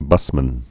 (bŭsmən)